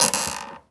chair_frame_metal_creak_squeak_12.wav